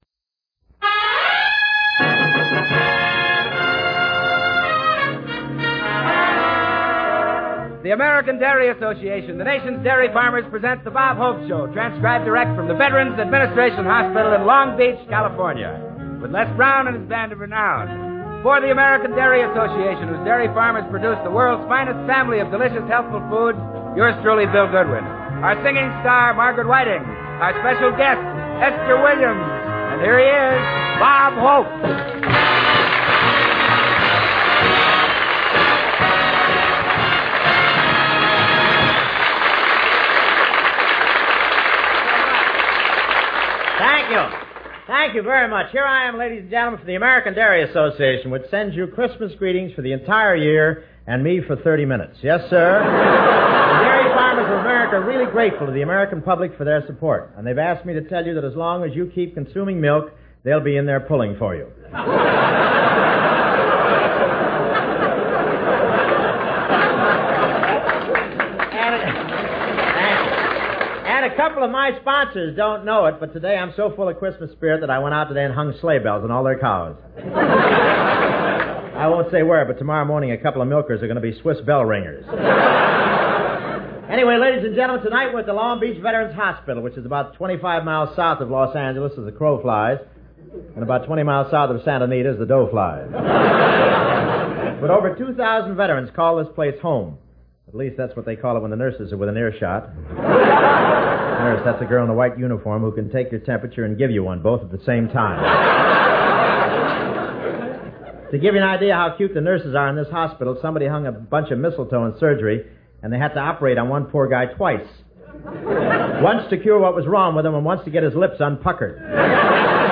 OTR Christmas Shows - From Long Beach Veteran\'s Hospital - Esther Williams - 1953-12-25 NBC The Bob Hope Show